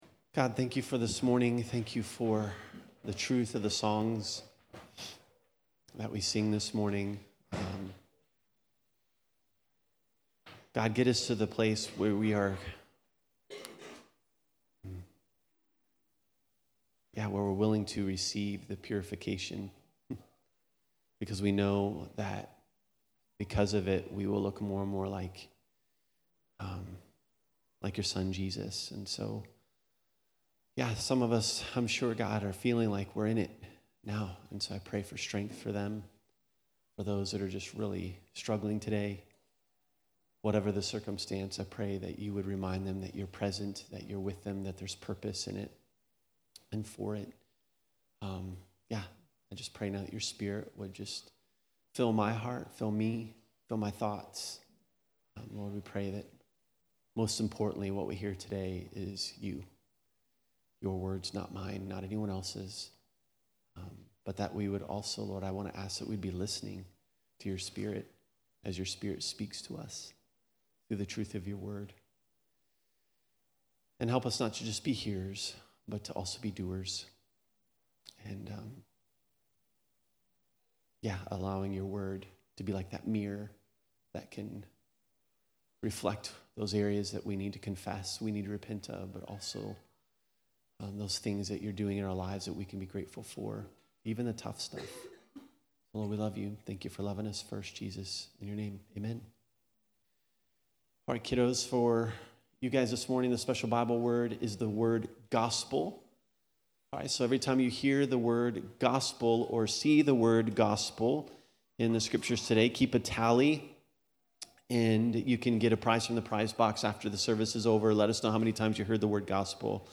In today’s teaching we’ll answer some Who, What, Where, and When questions about this book, learn Why it was written, and How it demands a response from everyone who reads it.